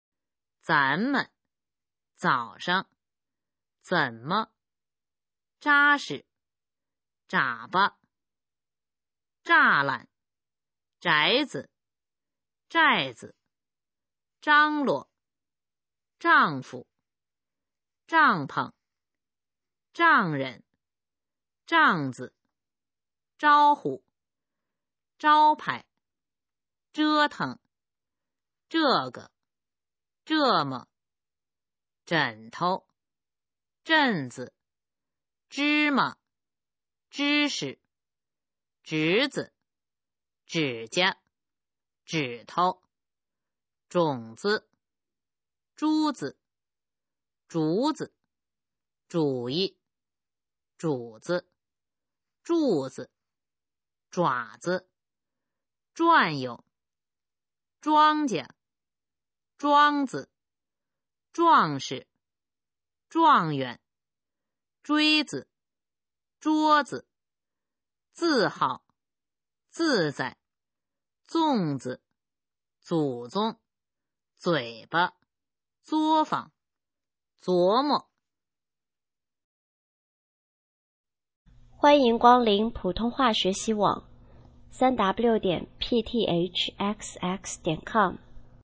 普通话水平测试用必读轻声词语表示范读音第501-545条